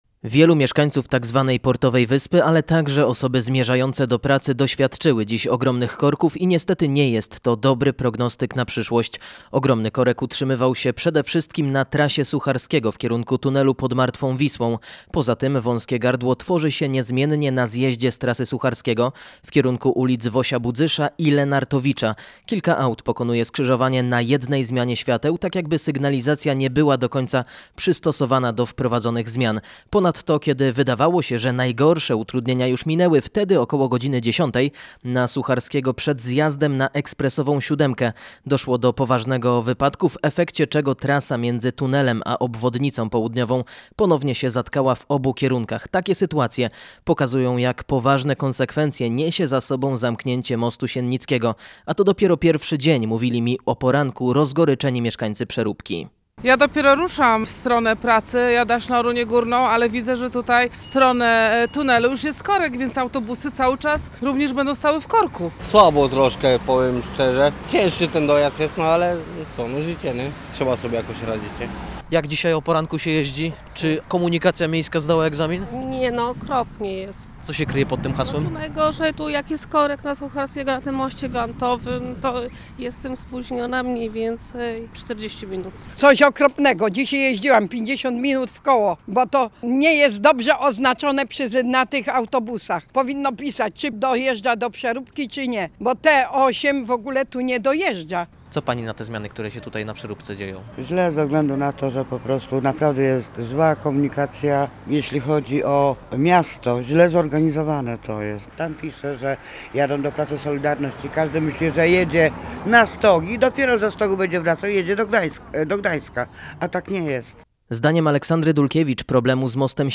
Rozmawiał miedzy innymi z osobami które dziś utknęły w korkach – Ich zdaniem miasto nie zdało tego egzaminu.